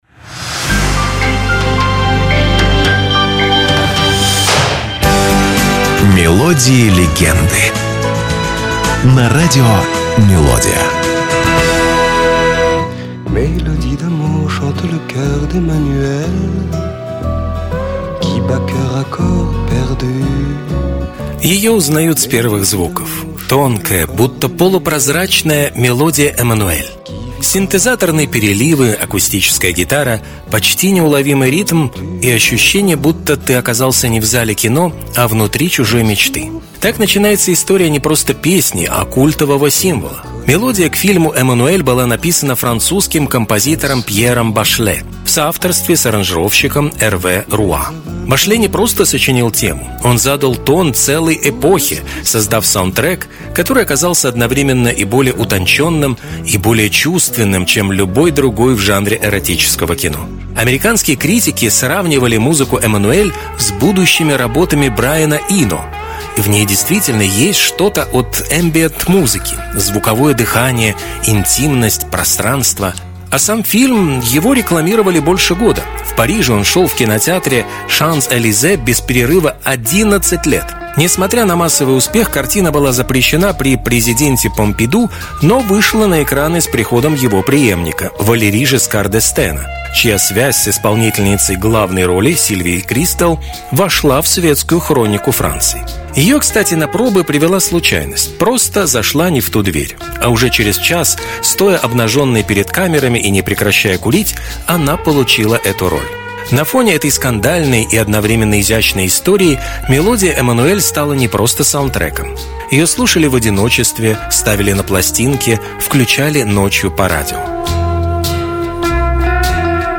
Это короткие музыкальные рассказы с душевным настроением, атмосферой ностальгии и лёгкой интригой.